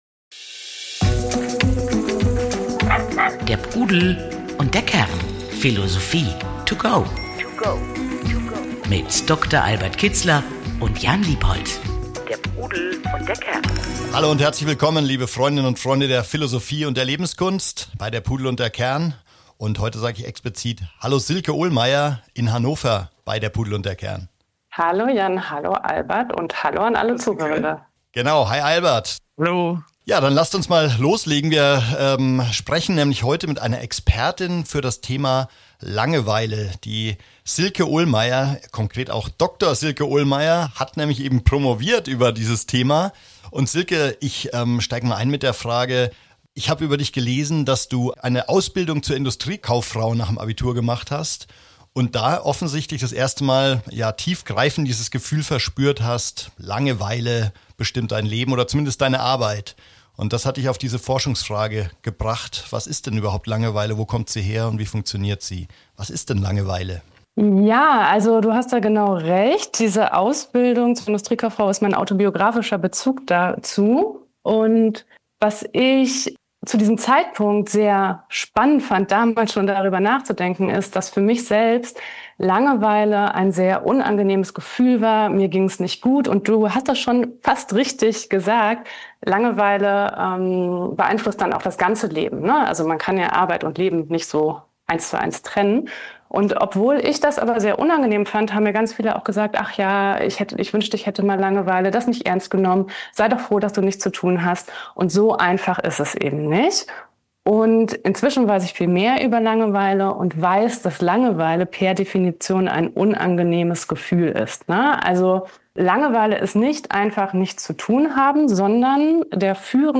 #190 Langeweile. Unangenehm, aber aufschlussreich. Interview